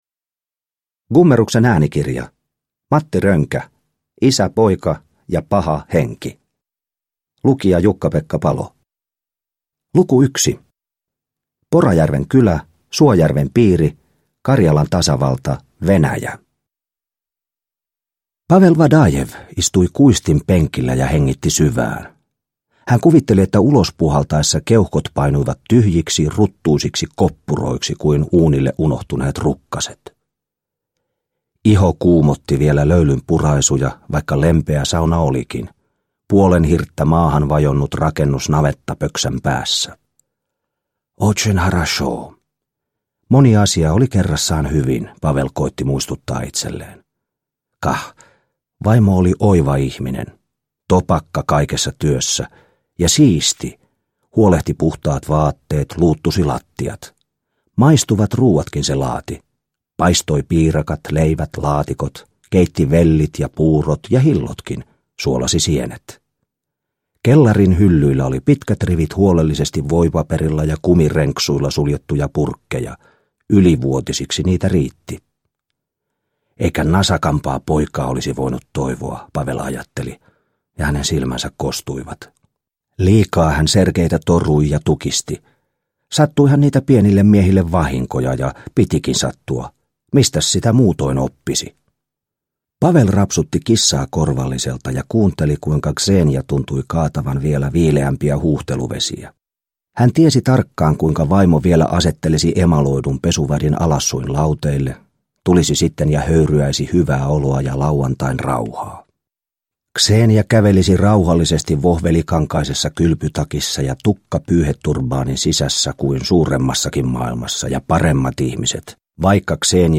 Isä, poika ja paha henki – Ljudbok – Laddas ner
Uppläsare: Jukka-Pekka Palo